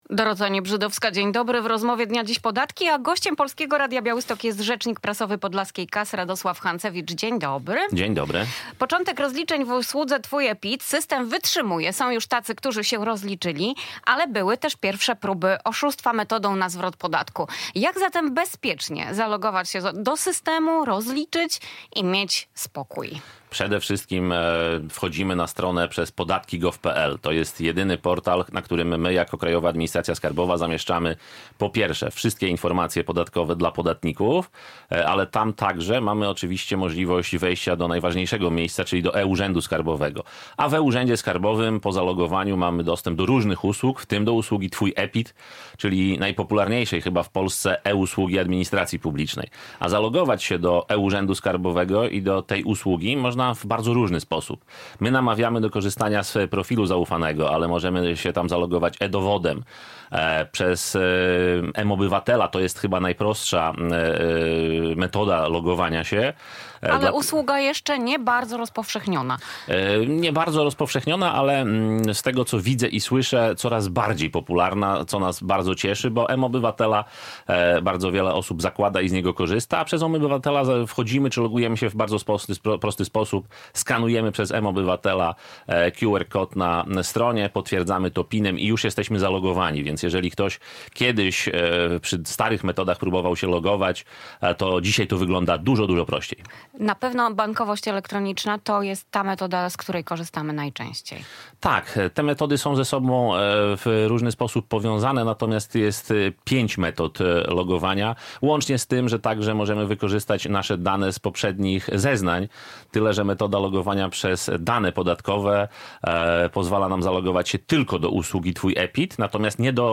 Gość
Studio Radia Bialystok